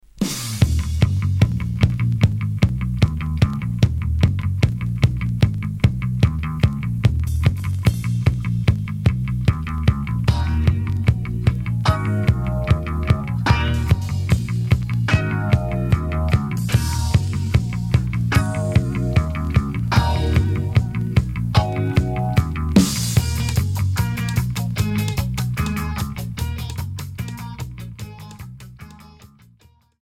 New Wave Punk Unique 45t